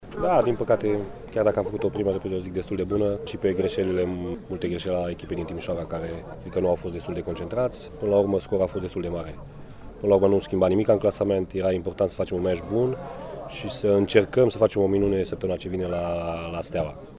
Declaraţii la final de meci: